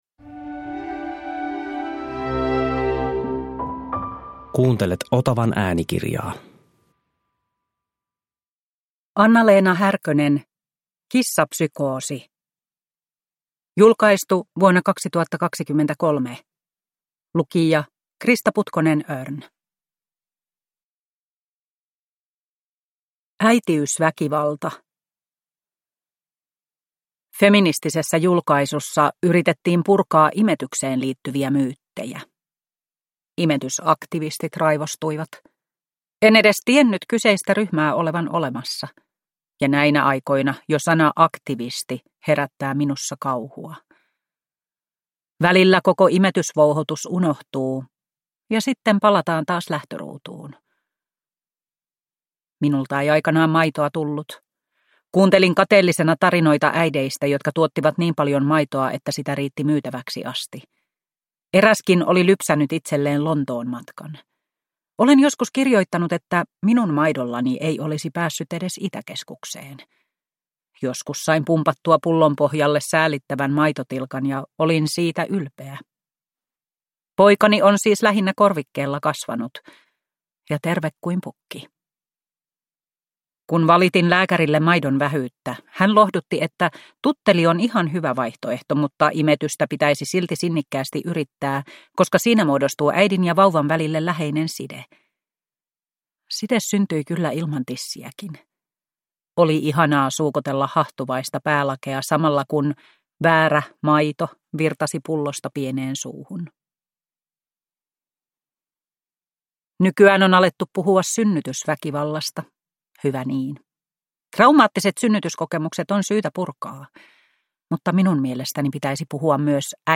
Kissapsykoosi – Ljudbok